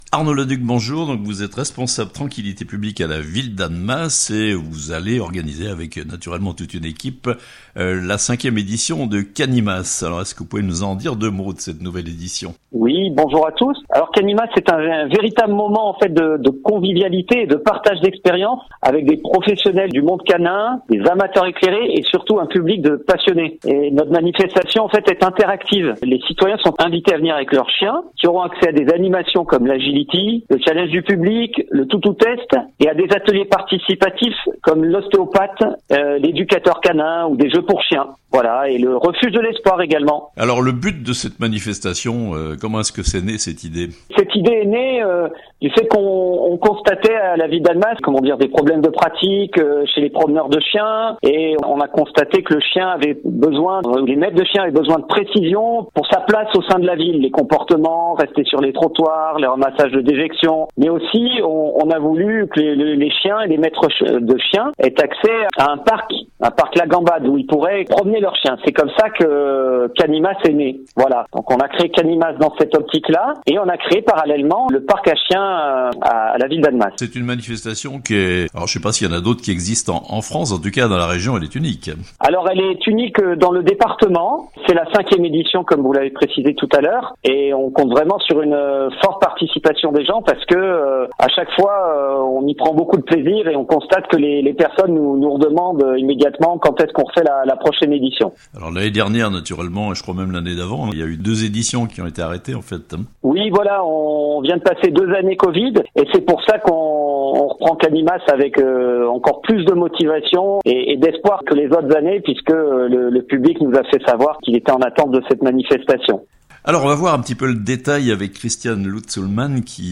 5ème édition de "Cani'masse" à Annemasse le samedi 9 avril (interviews)